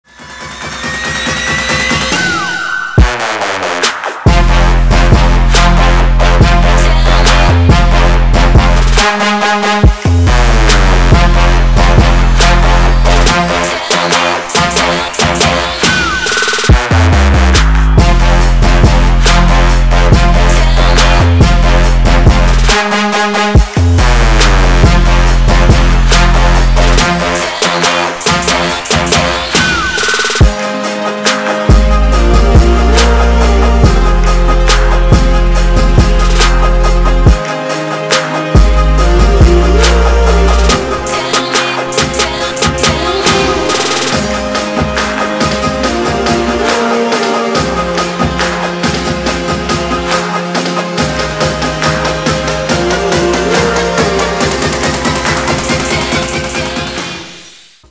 • Качество: 320, Stereo
Trap